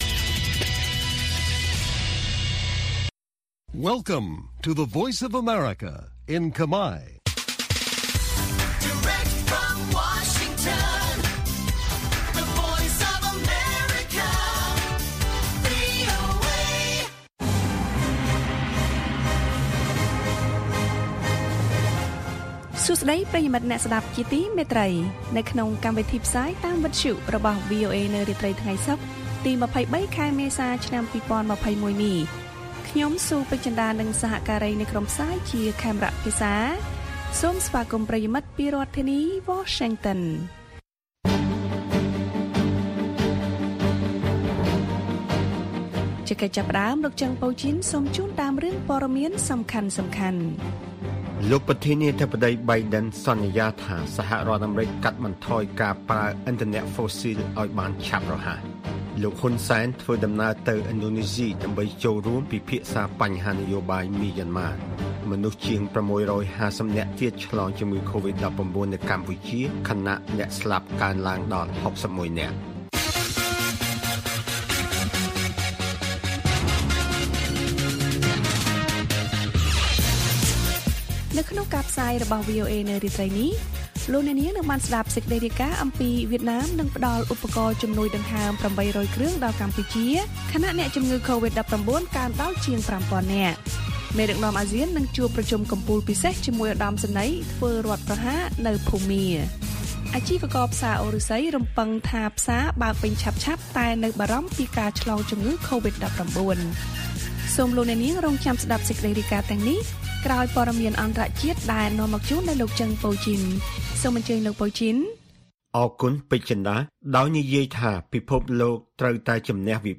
ព័ត៌មានពេលរាត្រី៖ ២៣ មេសា ២០២១